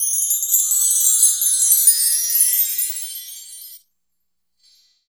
14 W.CHIME-R.wav